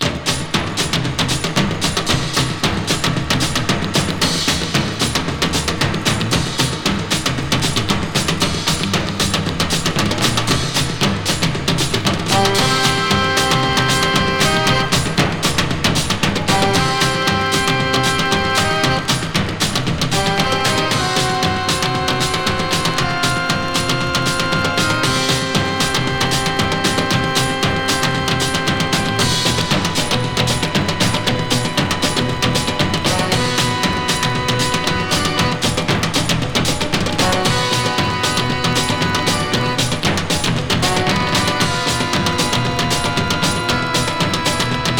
※キズ多ですが、感じさせない迫力ある音のMono盤です。
Rock, Surf, Garage, Lounge　USA　12inchレコード　33rpm　Mono